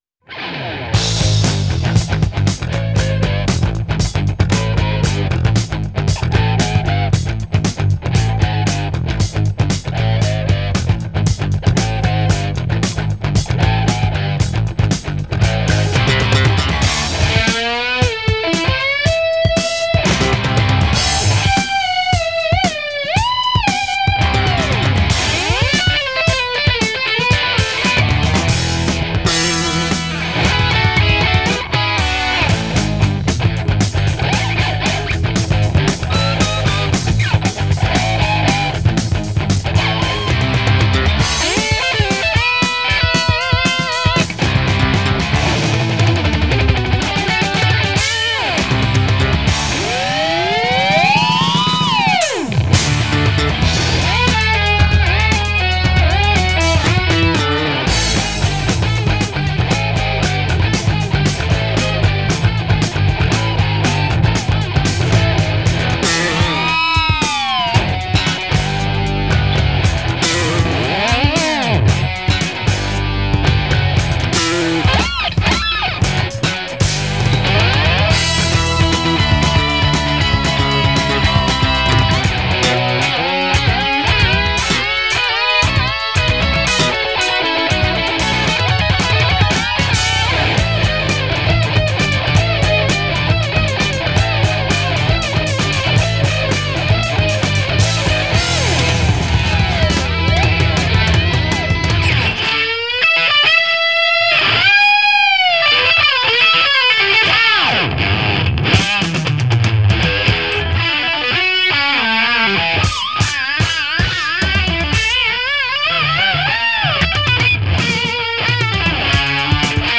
آهنگ راک گیتار الکتریک